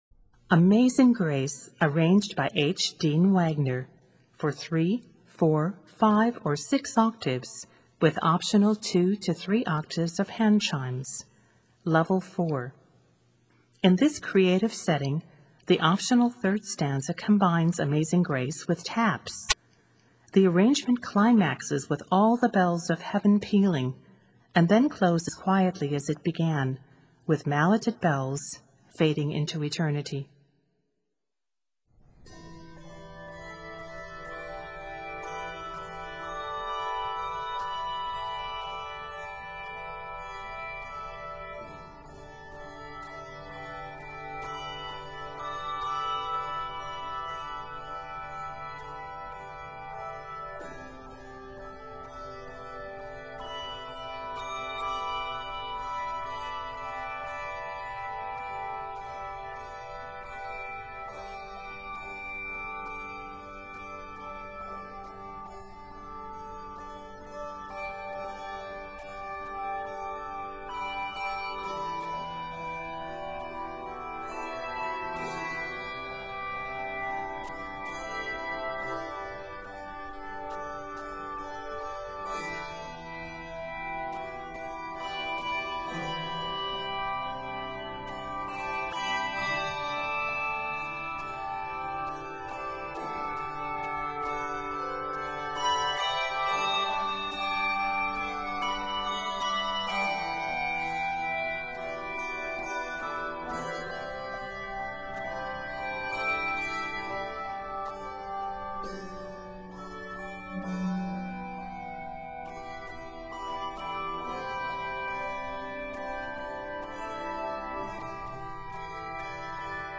It is set in G Major.